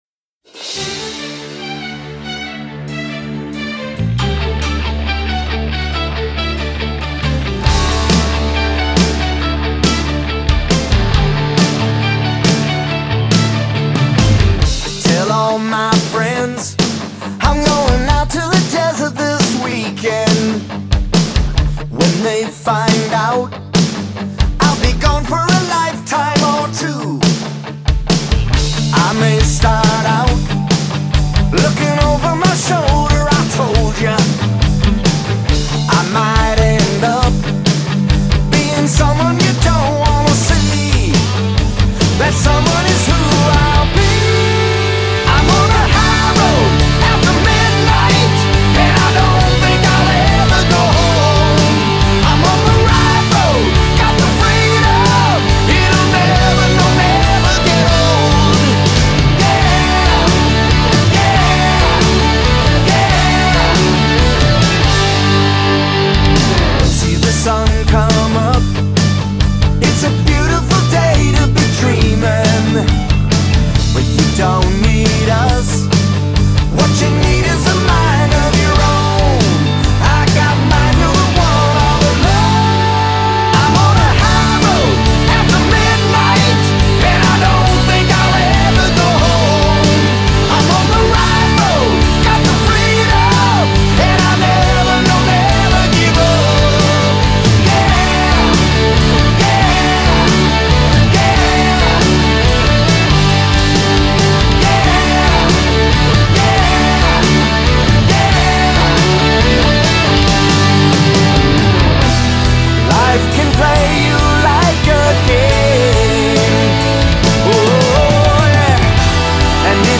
フックの効いた爽快な王道ハード・ロック・チューン。